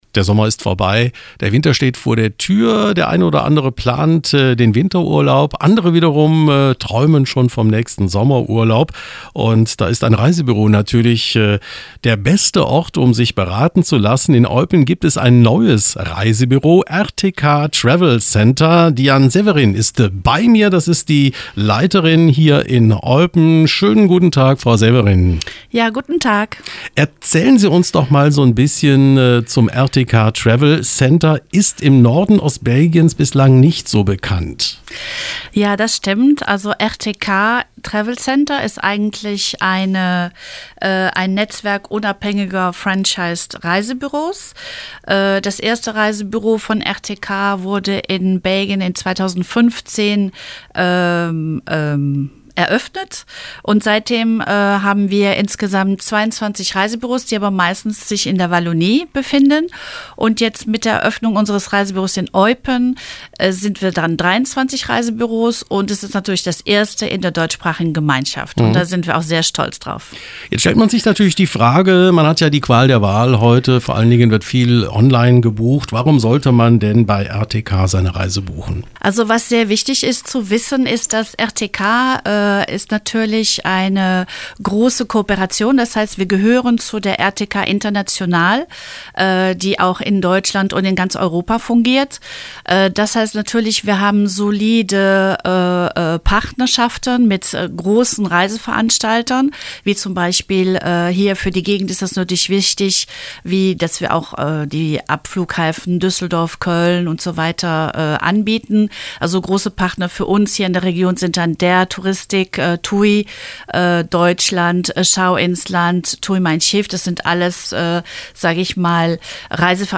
RTK-Travel-Center-Eröffnung-Interview.mp3